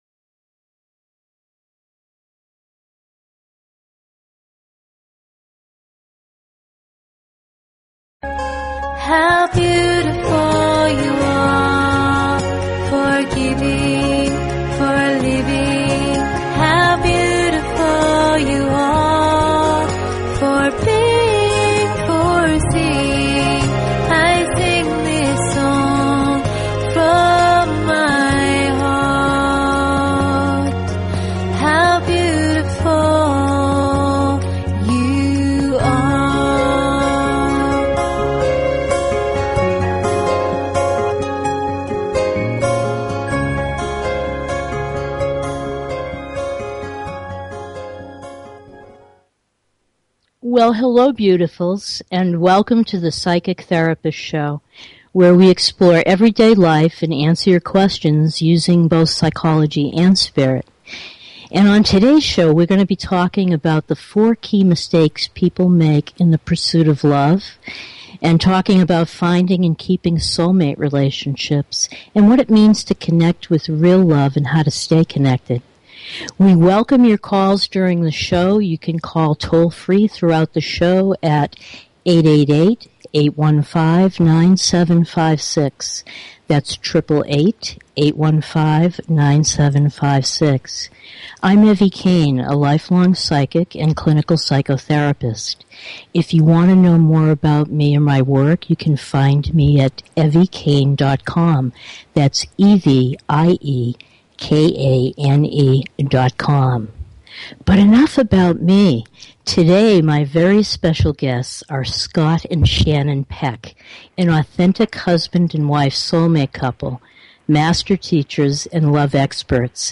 Talk Show Episode, Audio Podcast, Psychic_Therapist_Show and Courtesy of BBS Radio on , show guests , about , categorized as